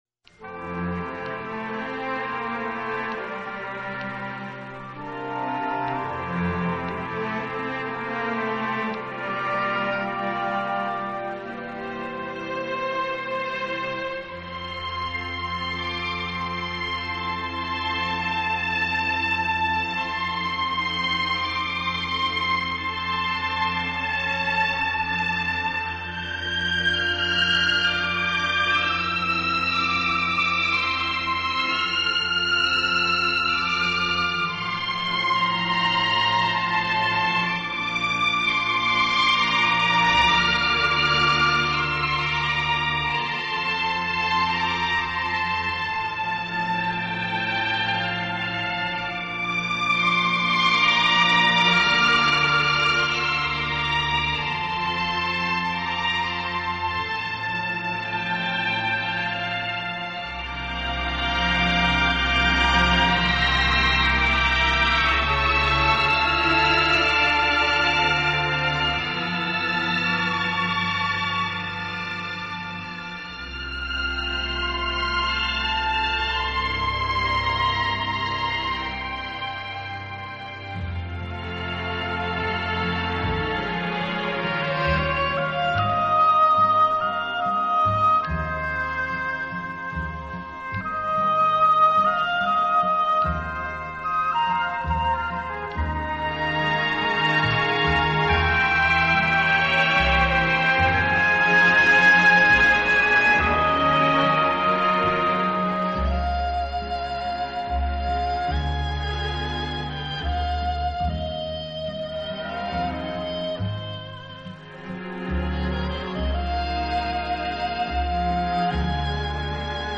这个乐团的演奏风格流畅舒展，
旋律优美、动听，音响华丽丰满。